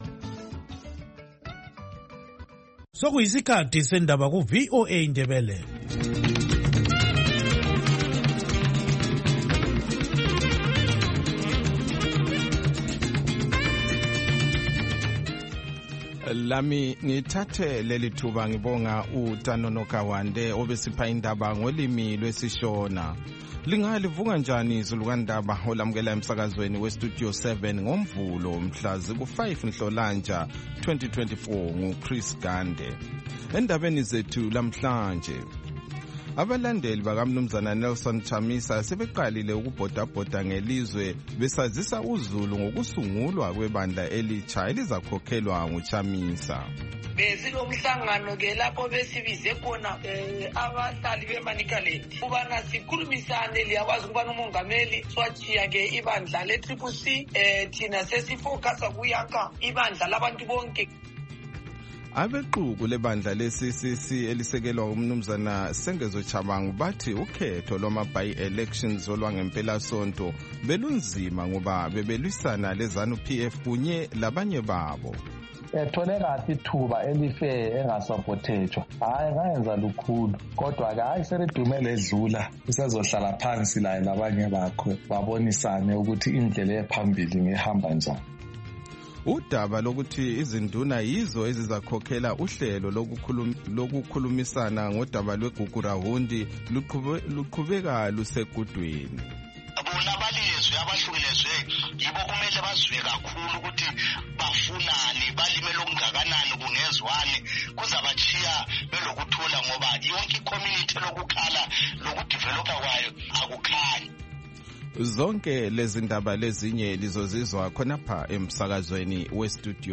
Silohlelo lwezindaba esilethulela lona kusukela ngoMvulo kusiyafika ngoLwesine emsakazweni weStudio 7 ngo6:30am kusiyafika ihola lesikhombisa - 7:00am.